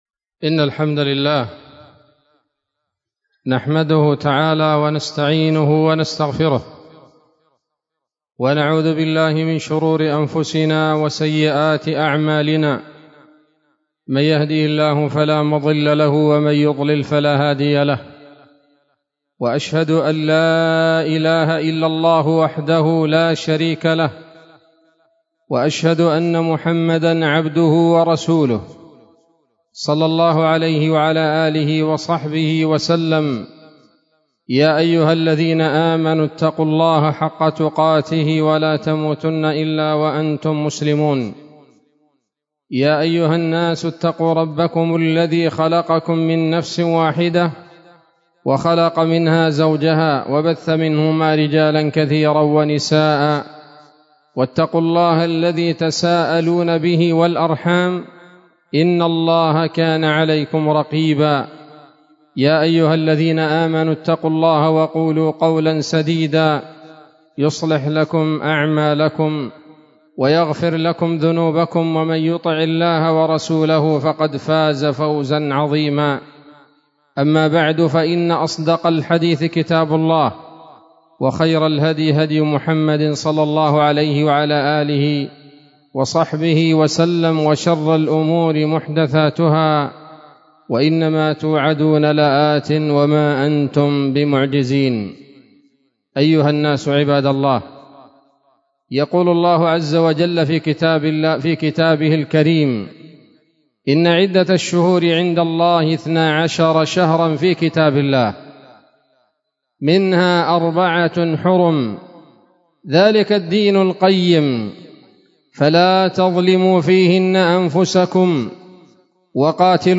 خطبة جمعة بعنوان: (( العجب في رجب )) 12 رجب 1444 هـ، دار الحديث السلفية بصلاح الدين